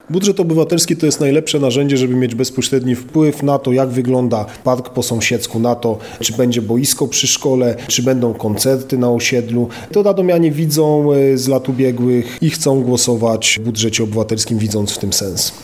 Co daje głosowanie w Budżecie Obywatelskim? O tym wiceprezydent Radomia Mateusz Tyczyński: